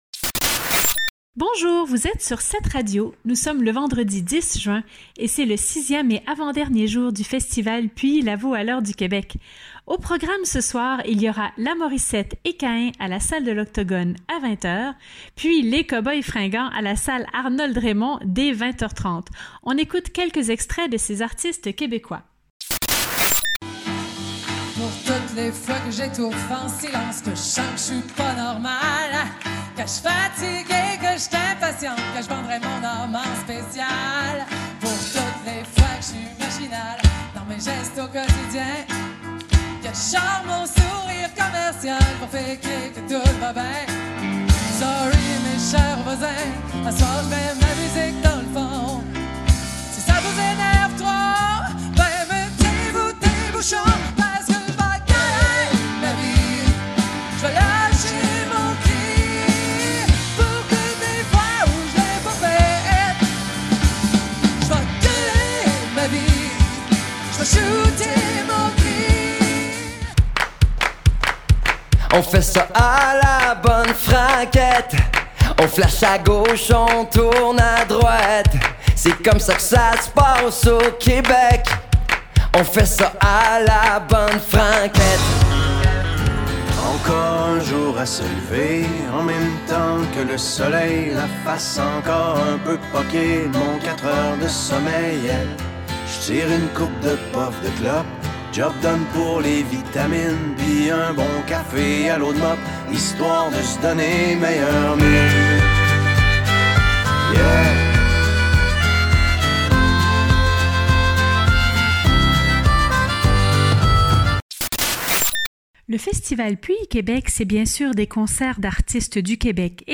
discute avec un passant chanceux